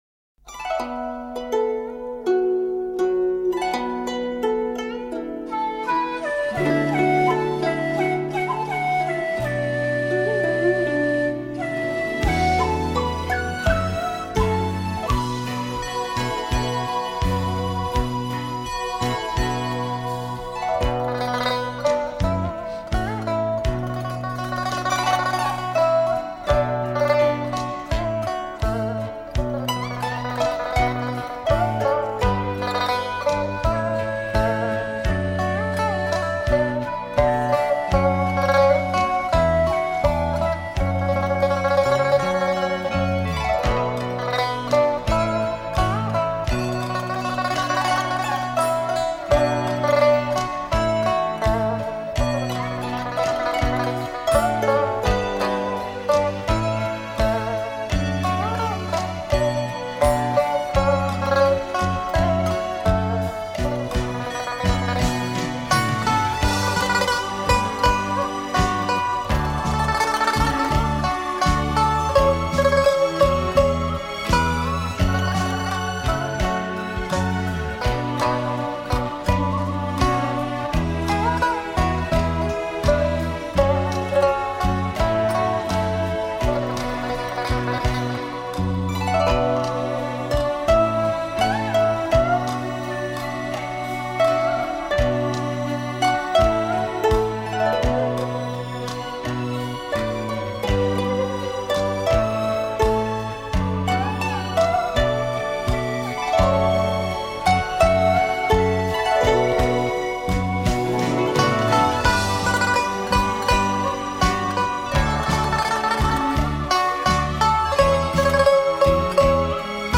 如诗如歌 管弦丝竹